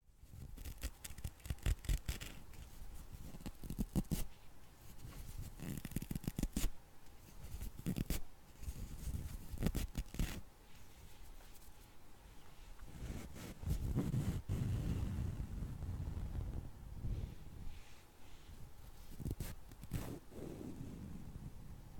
Звуки ваты
Хрустящие, шуршащие и мягкие аудиофайлы помогут расслабиться, создать атмосферу или использовать их в своих проектах.
Шорох рвущейся ваты